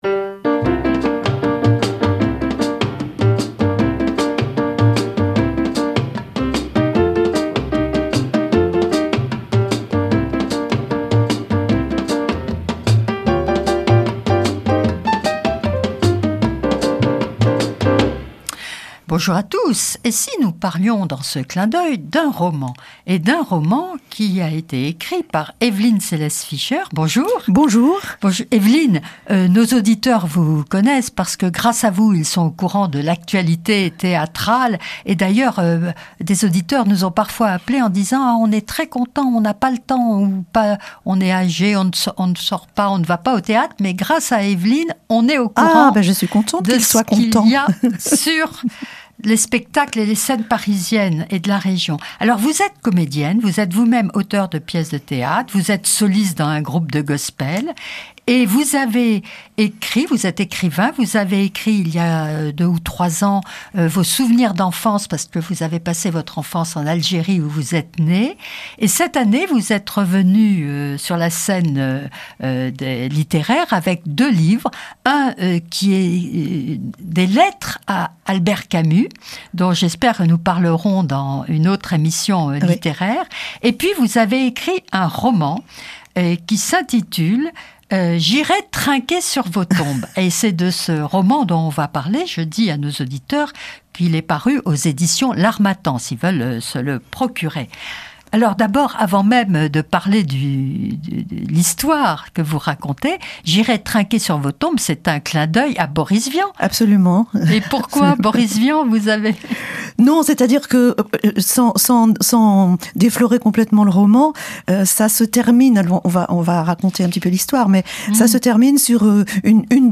interviewée par Fréquence protestante